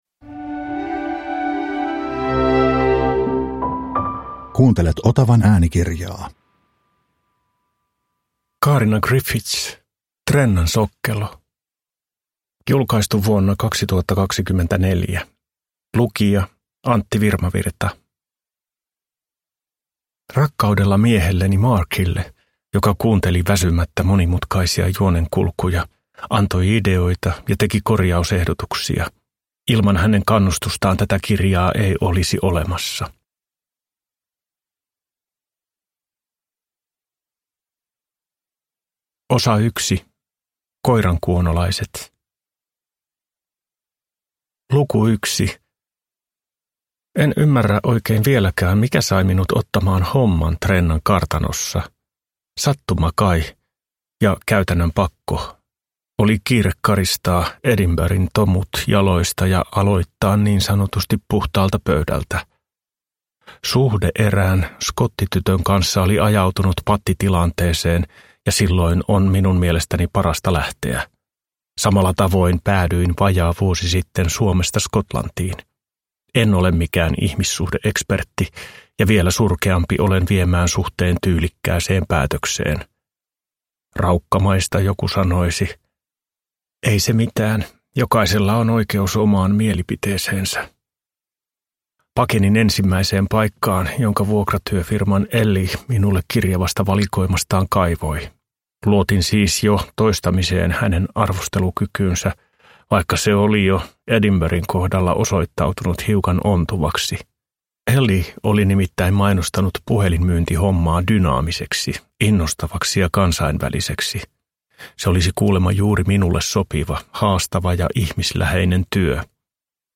Trennan sokkelo – Ljudbok